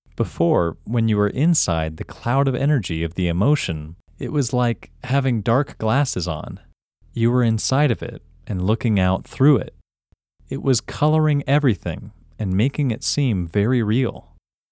OUT – English Male 18